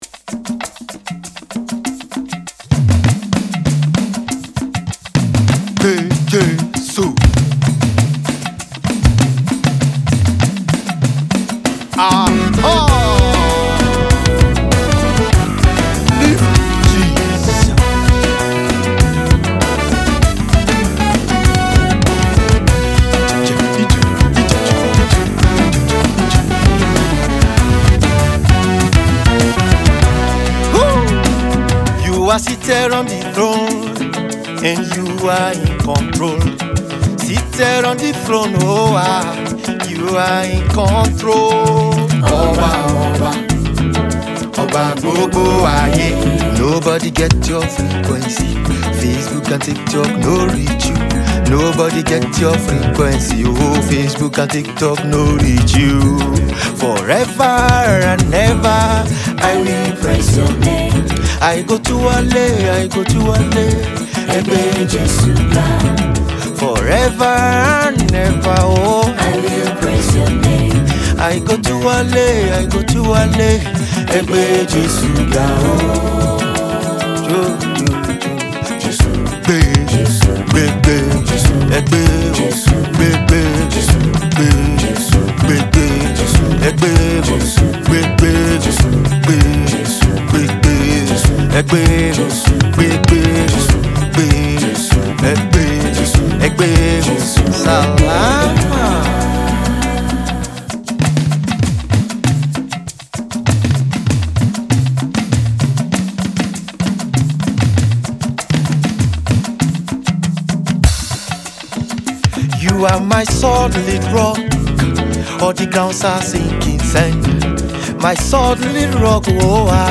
” a spirit-filled anthem of praise